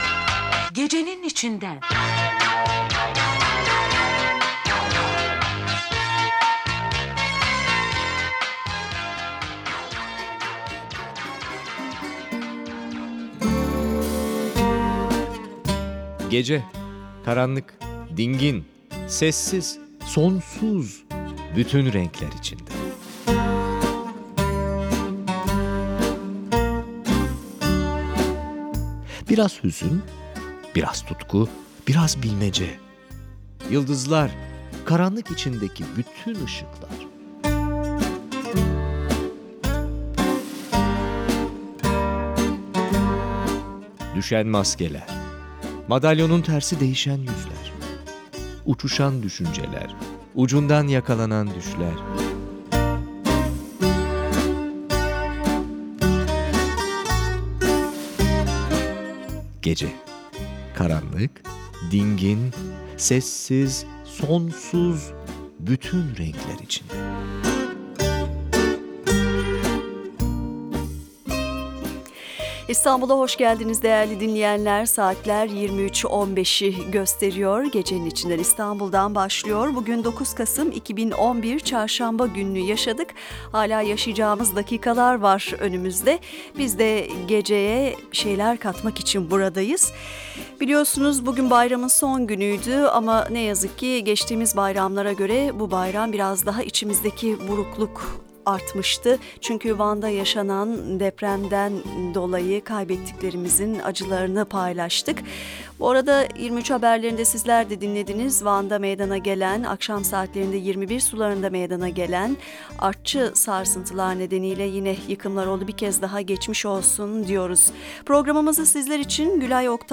Söyleşi: Gecenin İçinden - Konuk - TRT Radyo 1- 09 Kasım 2011